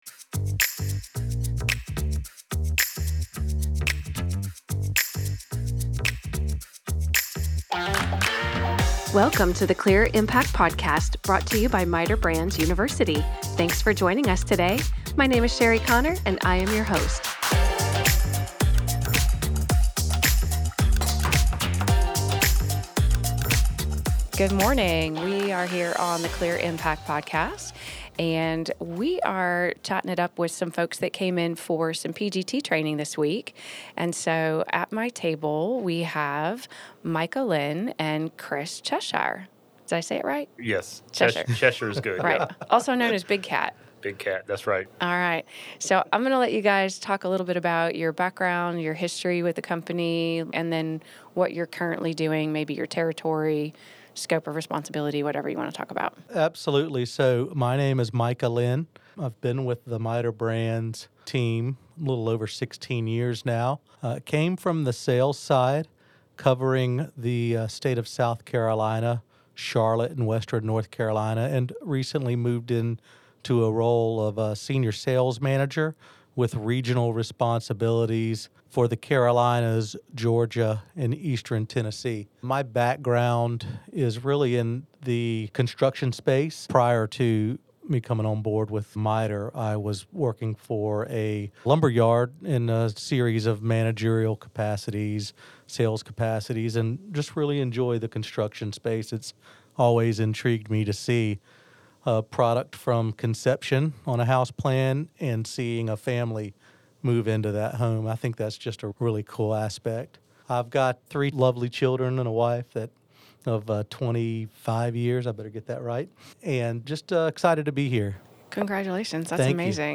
There is something special about great people having great conversations - and that is what you've got with these two MI sales professionals.